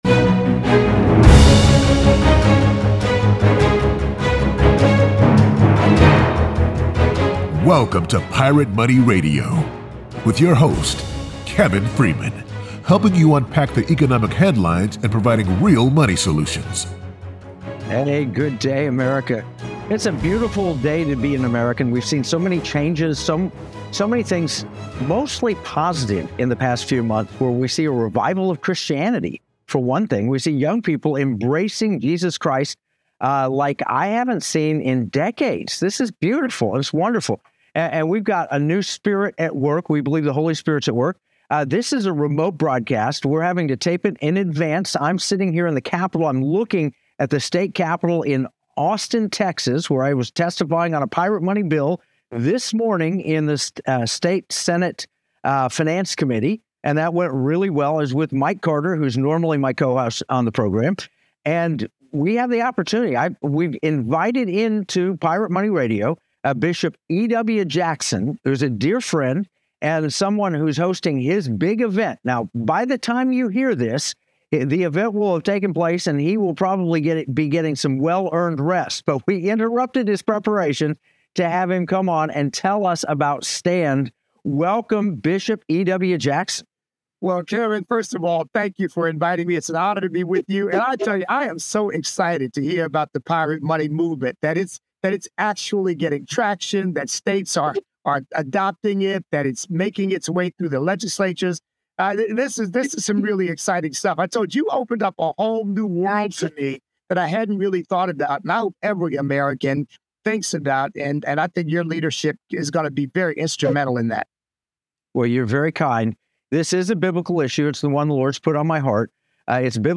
Broadcasting from Austin, Texas